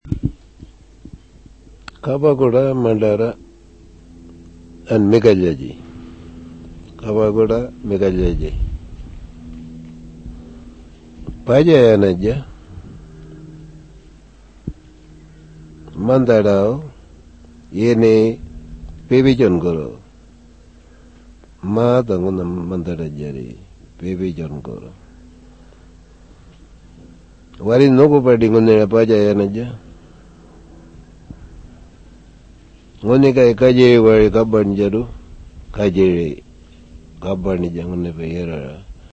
What does Jiwarli sound like?